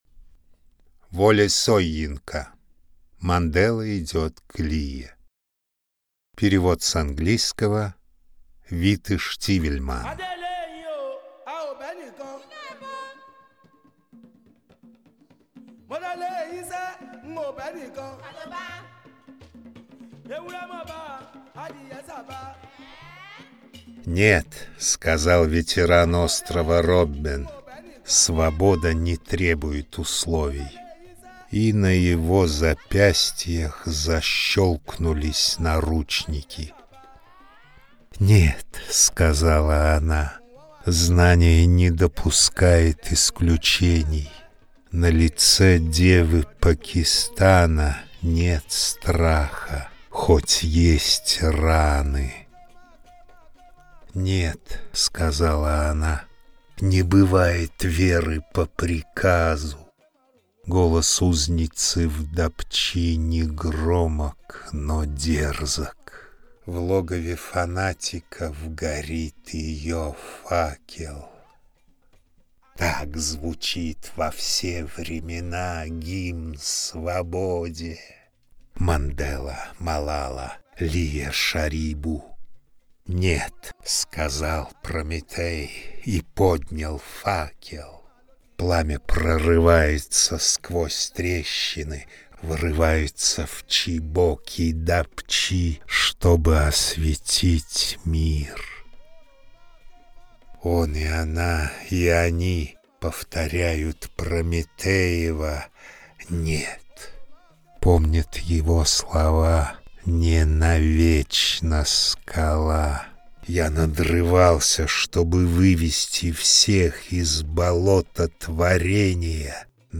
A reading of the poem in Russian